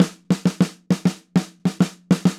Snare Drum Fill 02.wav